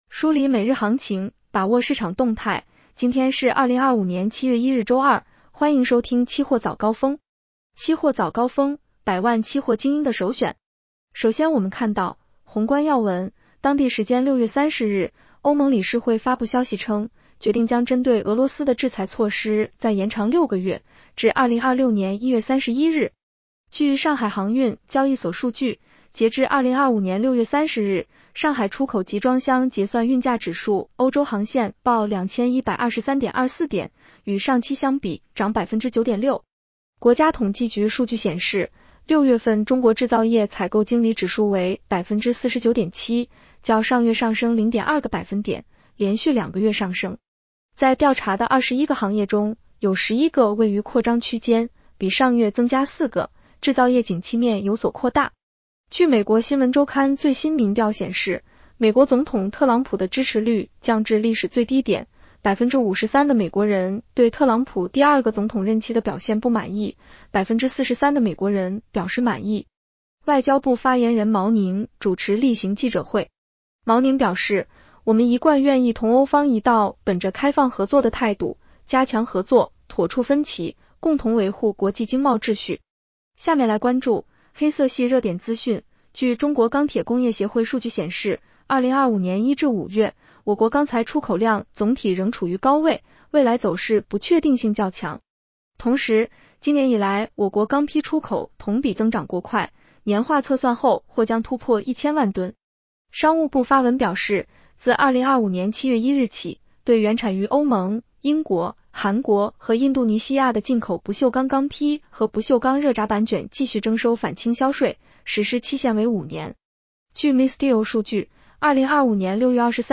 期货早高峰-音频版
女声普通话版 下载mp3